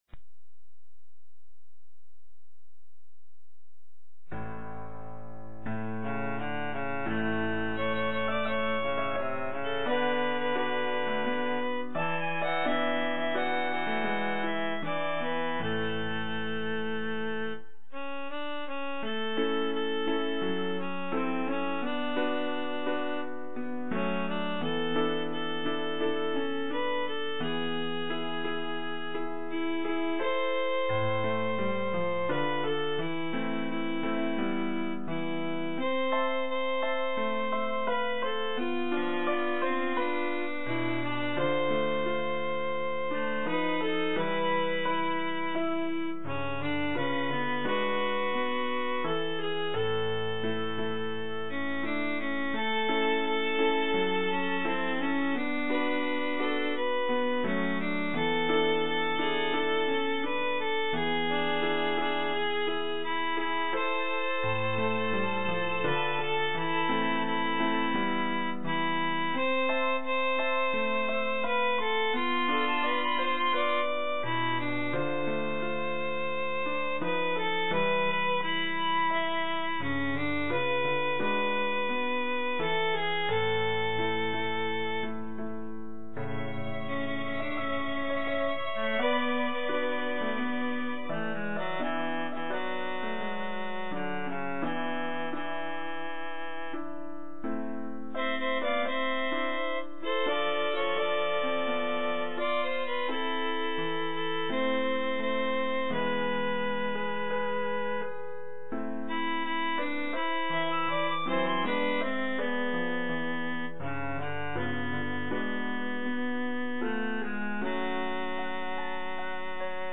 footsteps_viola_trio_with_vn_piano.mp3